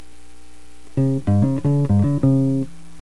la_mentira_bass.mp3